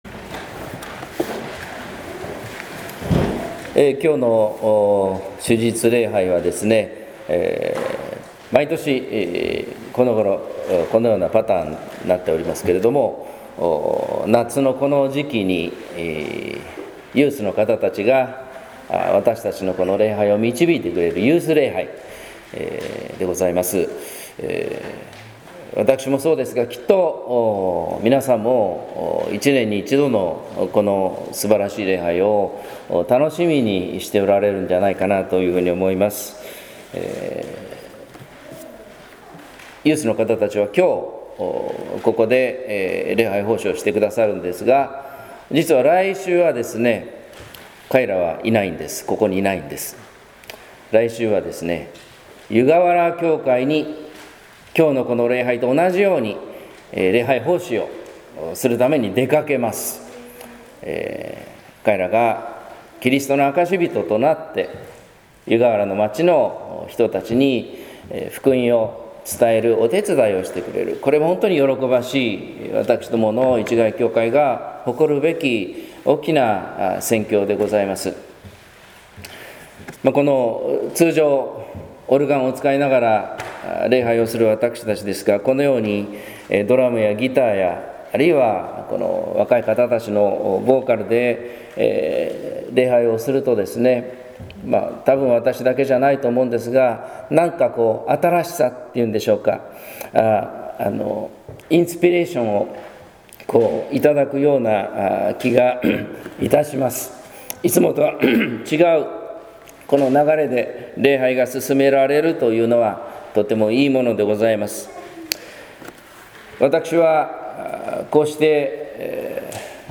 説教「祈りの奥深さ」（音声版）